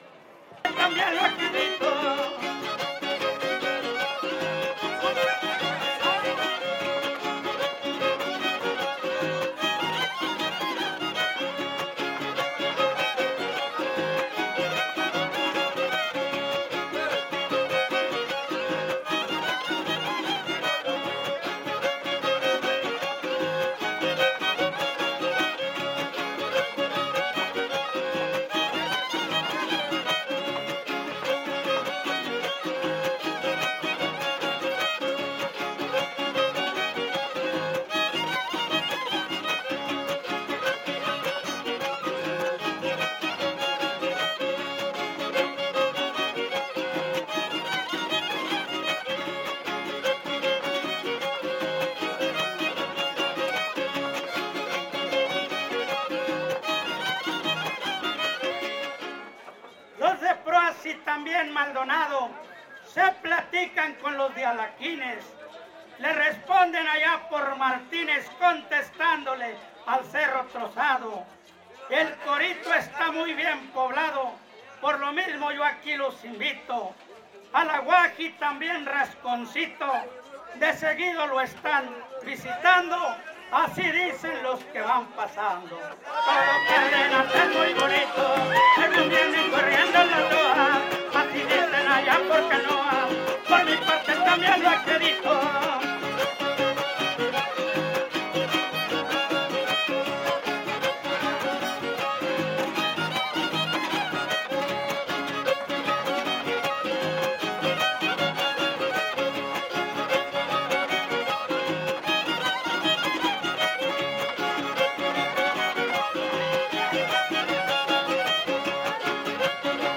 Cárdenas, San Luis Potosí
Poesía popular Huapango arribeño
Violín Vihuela Guitarra